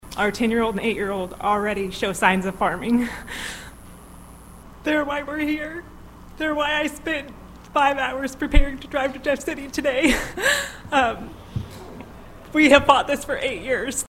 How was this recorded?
Wednesday, the Senate Commerce, Consumer Protection, Energy and the Environment Committee heard about two hours of testimony on the Senate version of the bill, SB 508, now championed by State Sen. Jason Bean, R-Holcomb.